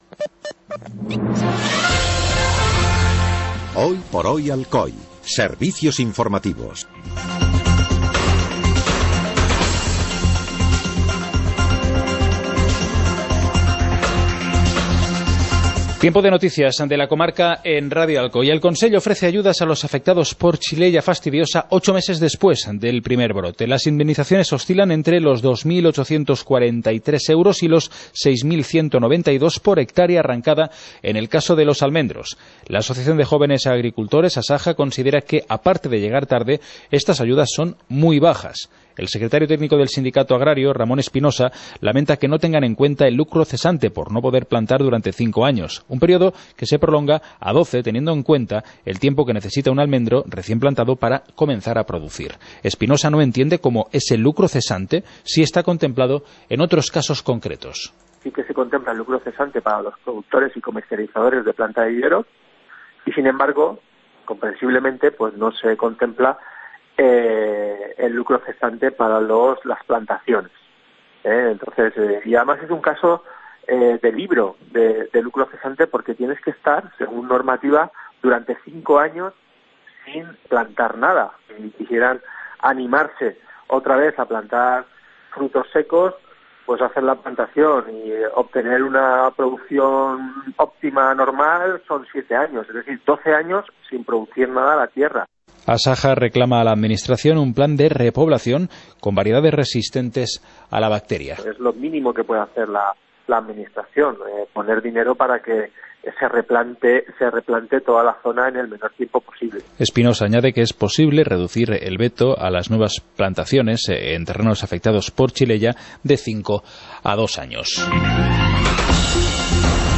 Informativo comarcal - viernes, 16 de febrero de 2018